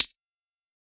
Index of /musicradar/ultimate-hihat-samples/Hits/ElectroHat B
UHH_ElectroHatB_Hit-05.wav